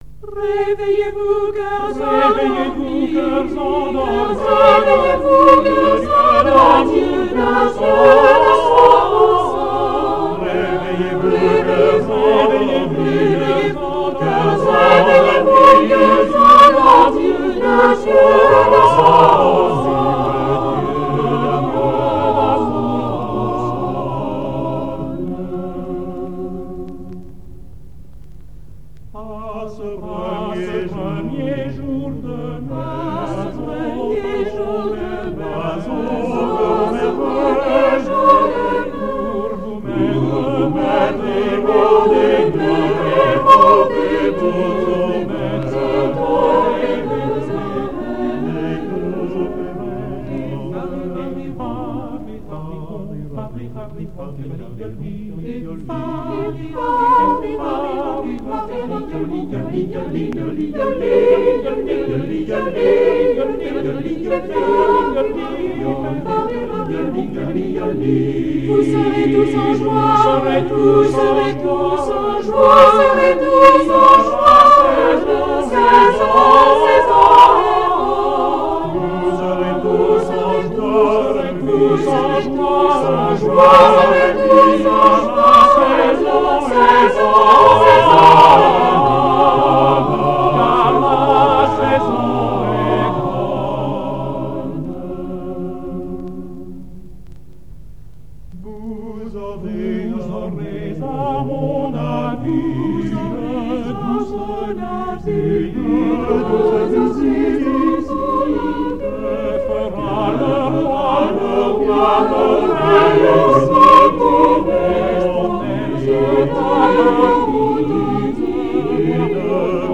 musique d'époque (Clément Janequin: Le Chant des Oiseaux)